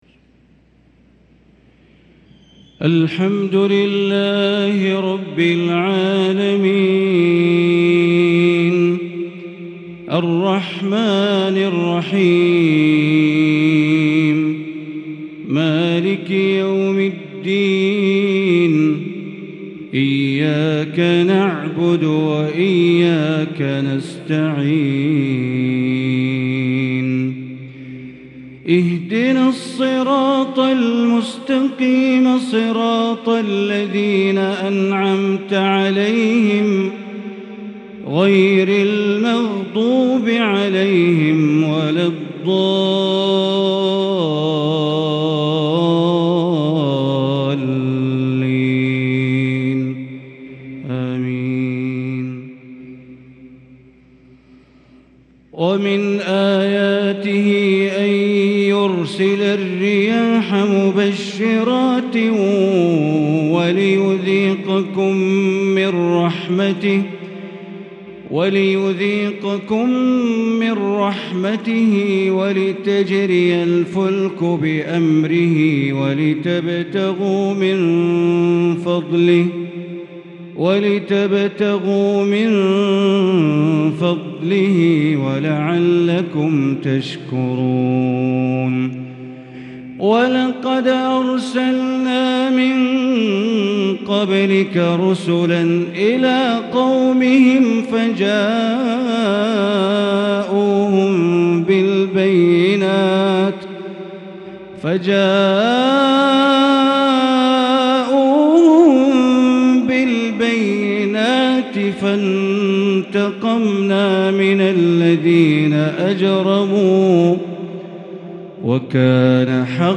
عشاء الثلاثاء 11 محرم 1444هـ | من سورتي الروم و ق | Isha prayer from saurat al rom and Qaf 9-8-2022 > 1444 🕋 > الفروض - تلاوات الحرمين